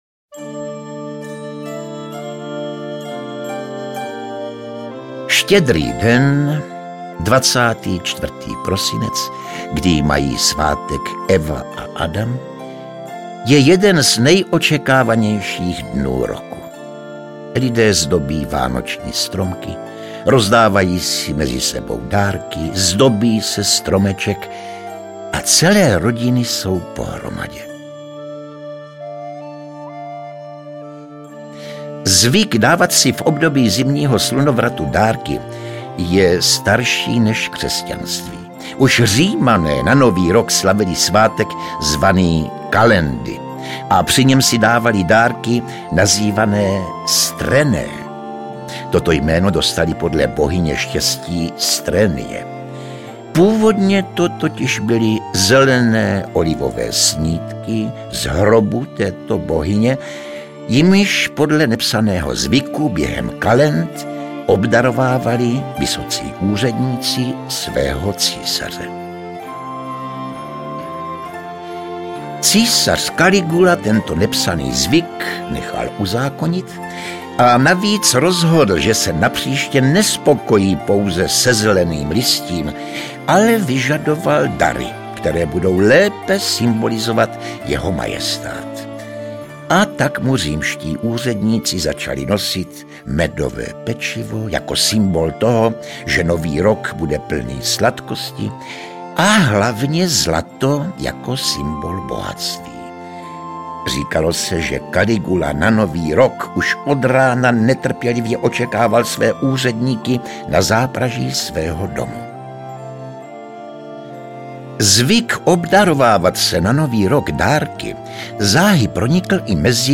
Audio kniha
Ukázka z knihy
Atmosféru Vánoc našeho dětství i dětství našich babiček připomíná speciálně sestavená kolekce povídek a vyprávění. Herci Josef Somr, Josef Dvořák, Tomáš Töpfer, Miroslav Táborský, Vladimír Javorský, Pavel Kříž a David Matásek jsou zárukou skvělého posluchačského zážitku.